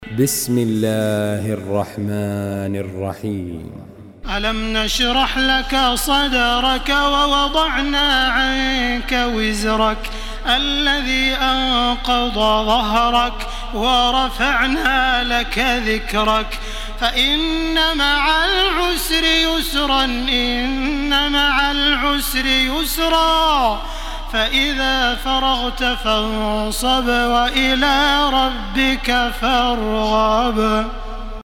Surah Inşirah MP3 by Makkah Taraweeh 1434 in Hafs An Asim narration.
Murattal Hafs An Asim